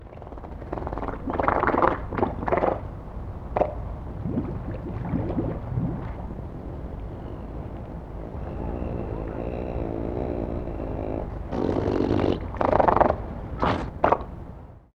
Seehund – Natur erleben – beobachten – verstehen
Hier können Sie sich die Stimme eines Seehundes anhören: Lautfolgen und Blubbern von Seehundmännchen
1388-seehund_lautfolgen_und_blubbern_maennchen-soundarchiv.com_.mp3